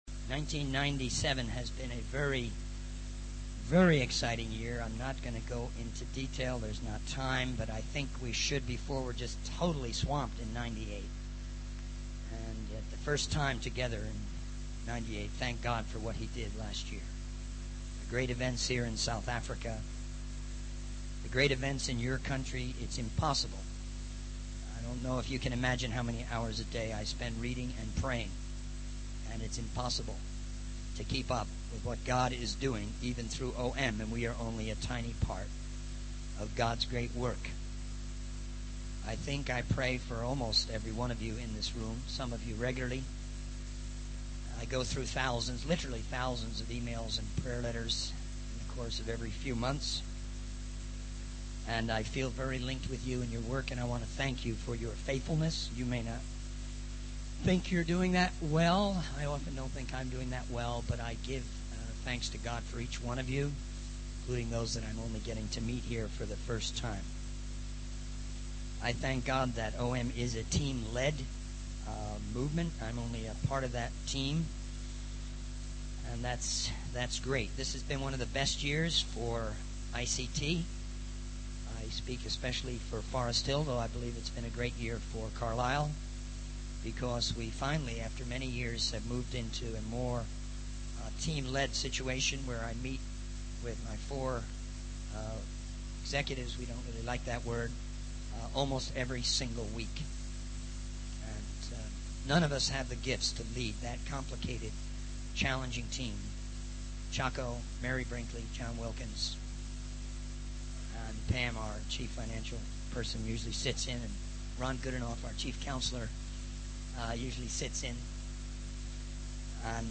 In this sermon, the speaker discusses the importance of unity within the Christian community. He emphasizes the need to avoid 'friendly fire,' which refers to conflicts and divisions within the body of Christ. The speaker encourages the audience to reexamine their doctrinal beliefs and to beware of negative reports that can hinder unity.